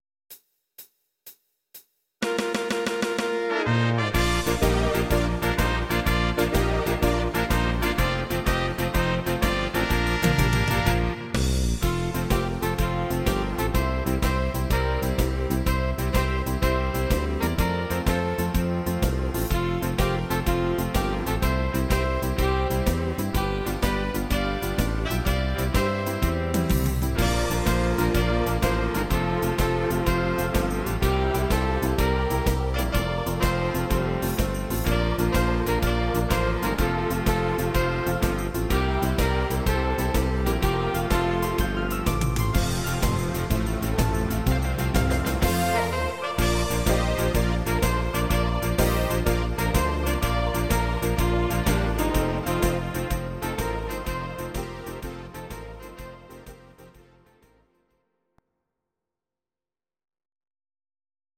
These are MP3 versions of our MIDI file catalogue.
Please note: no vocals and no karaoke included.
Your-Mix: Traditional/Folk (1154)